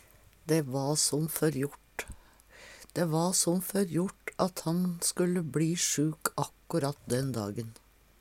dæ va som førjort - Numedalsmål (en-US)